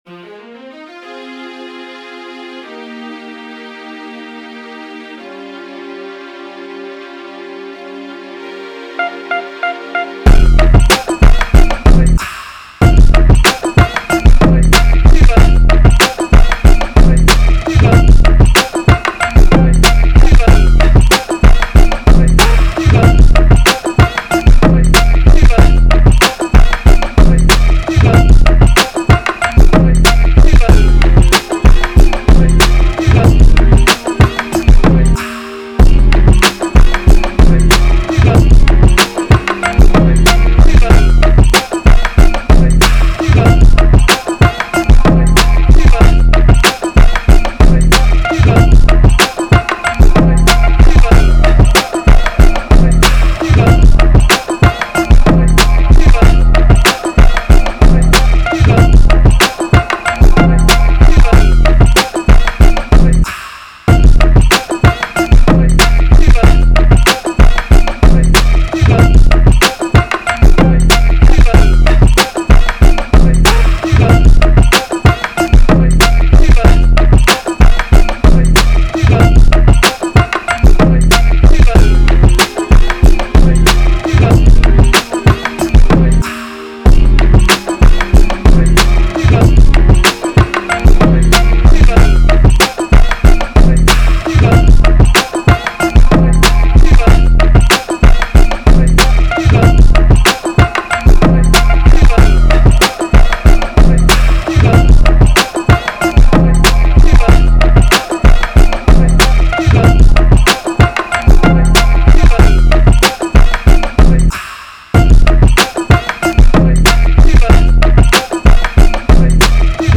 Fm 94